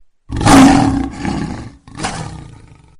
Dog Growl
Dog Growl is a free animals sound effect available for download in MP3 format.
485_dog_growl.mp3